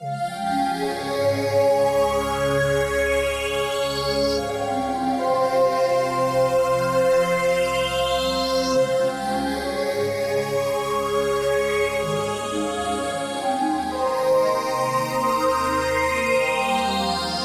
描述：调：Fmin 速度：110bpm 有点像Breakbeat和80年代的坏电影混合在一起。